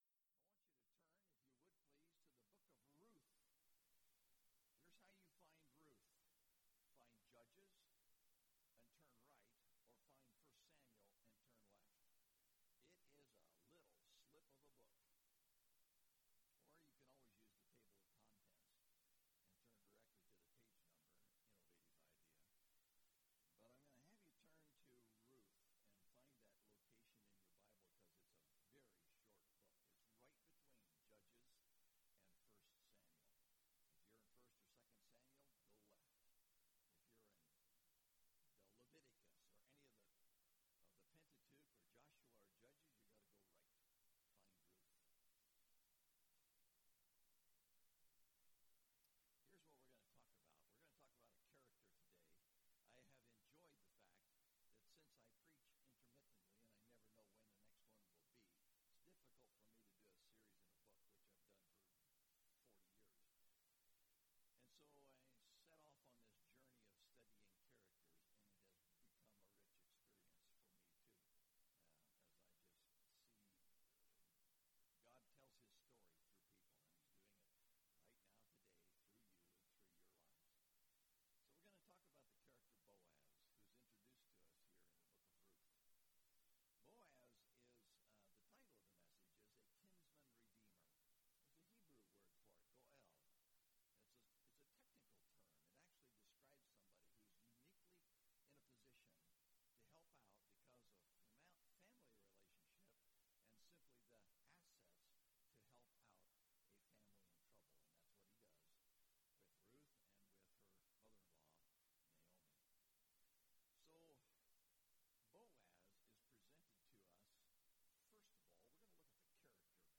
Boaz – A Kinsman-Redeemer (Ruth) – Mountain View Baptist Church